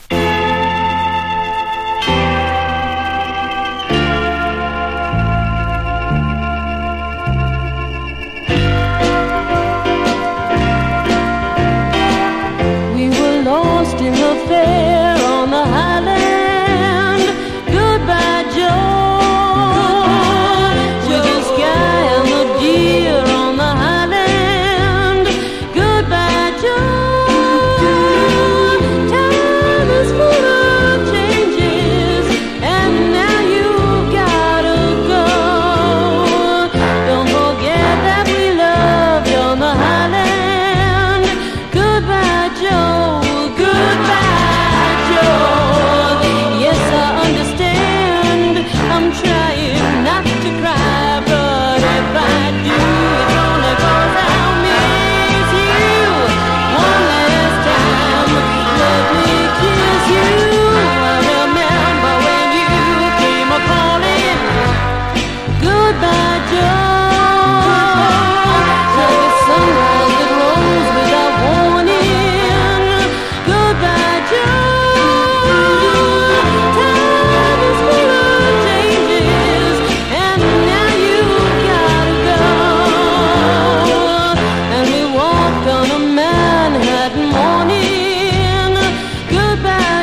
1. 60'S ROCK >
SSW / FOLK